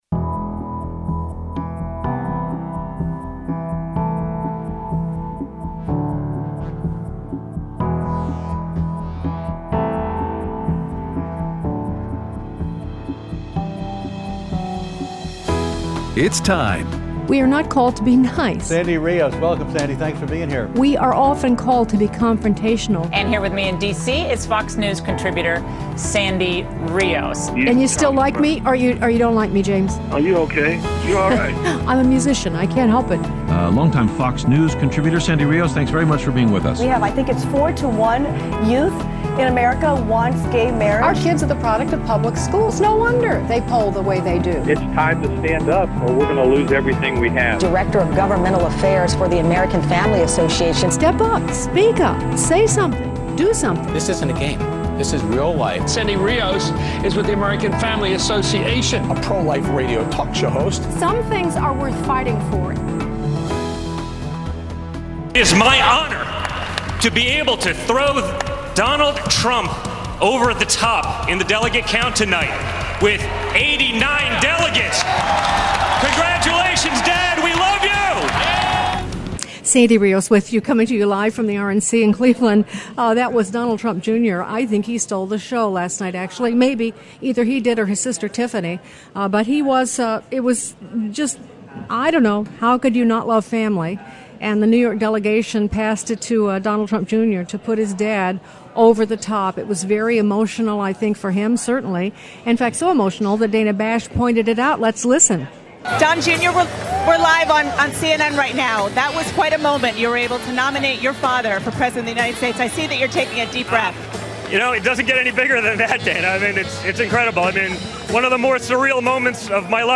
Live at the RNC Day 3 - 7-20-16